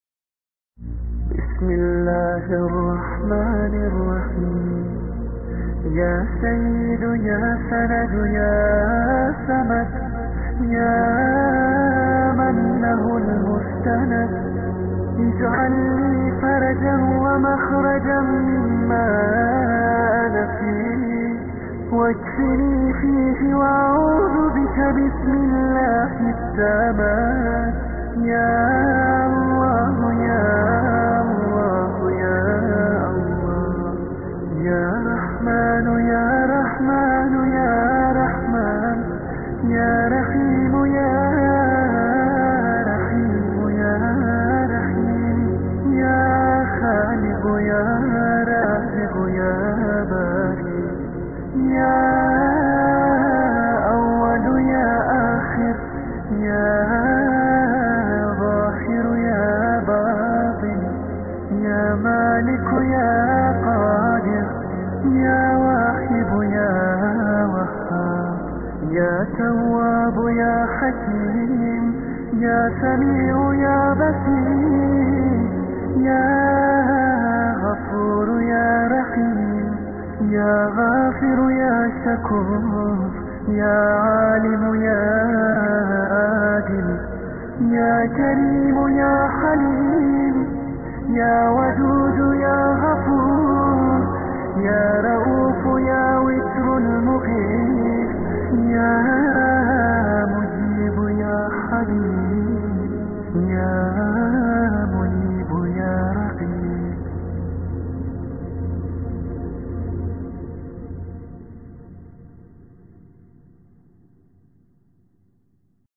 دعای معراج صوتی:
شنیدن دعا با تلفظ صحیح و لحن سنتی، به درک بهتر معانی و آشنایی با شیوه درست قرائت کمک می‌کند.